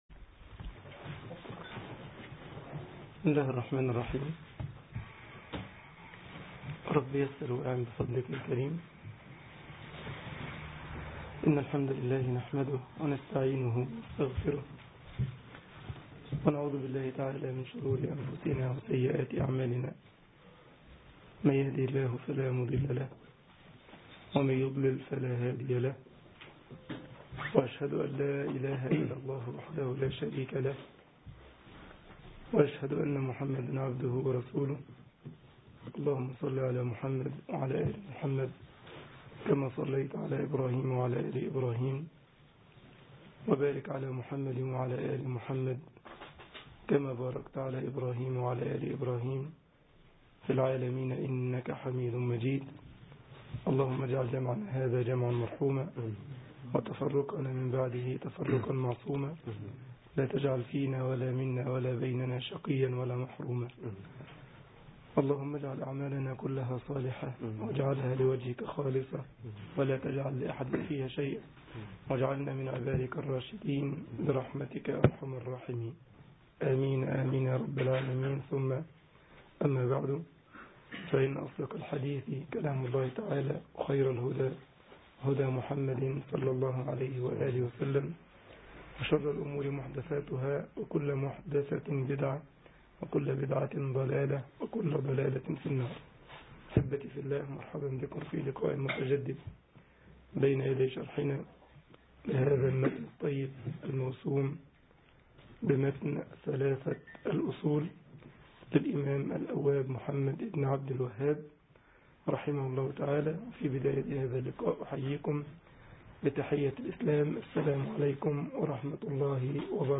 محاضرة
جمعية الشباب المسلمين بسلزبخ ـ ألمانيا